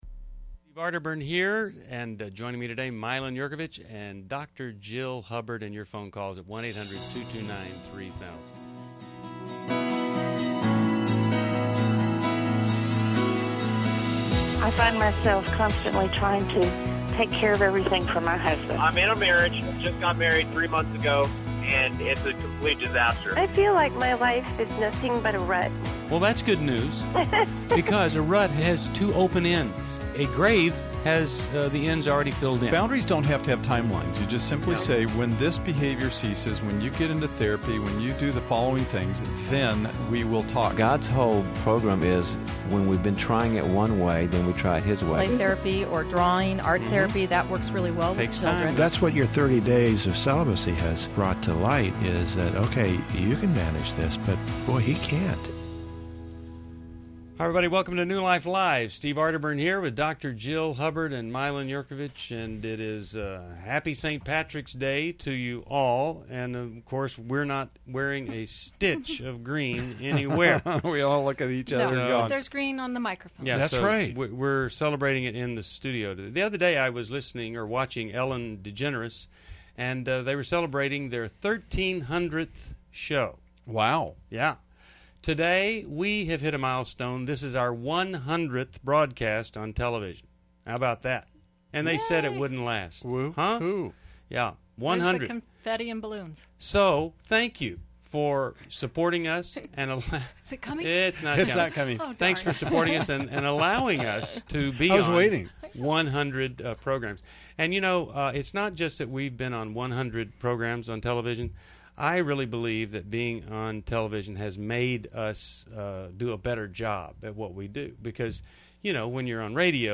Explore dating, grief, and family dynamics on New Life Live: March 17, 2011. Join hosts as they offer guidance on relationships and healing.